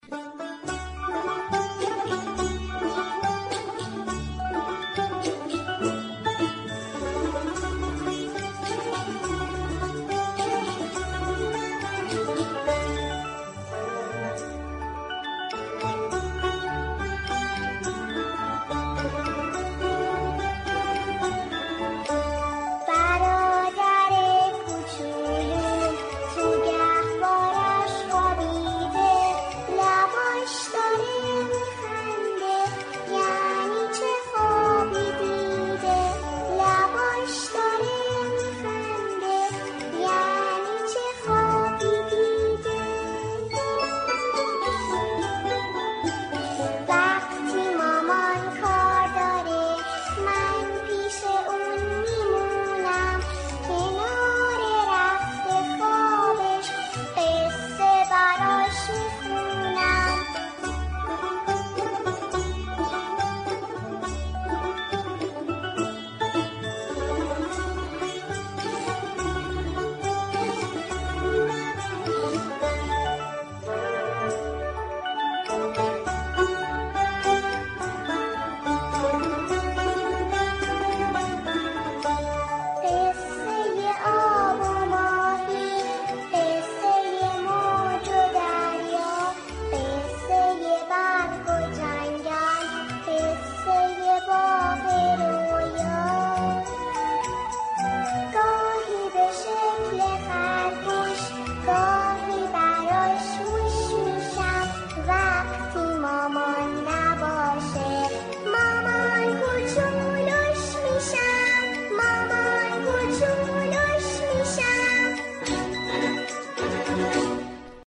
• لالایی آهنگ لالایی